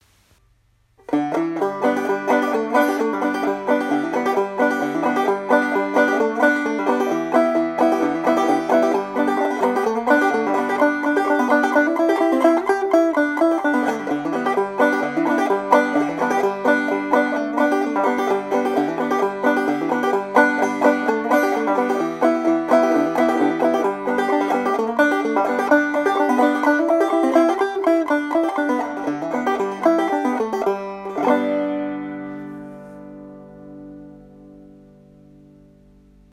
This banjo features a 12" or 11" stainless steel spun-over rim with a REMOVABLE Dobson tone ring, richlite fingerboard, and either a walnut or cherry neck.
Good sustain and resonance.  Very responsive and easy to hear yourself play.
12”-Rambler-Walnut-Dobson.m4a